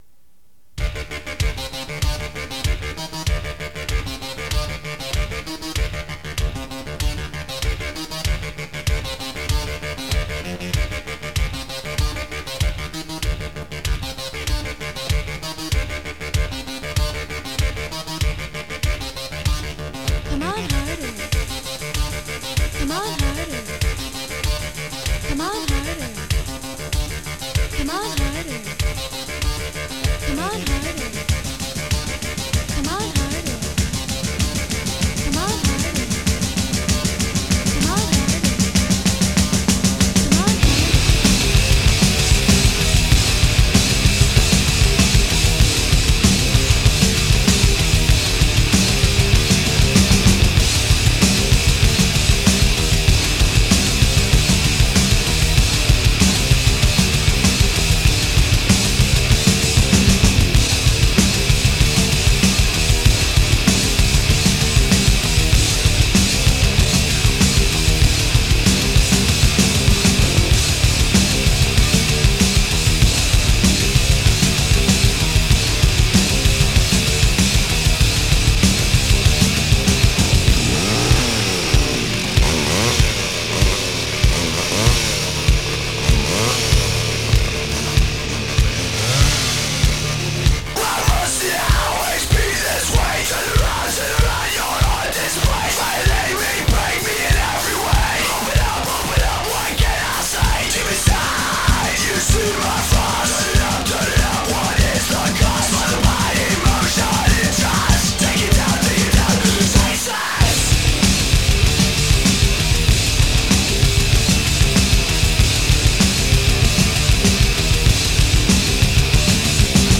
guitar heavy electrocore band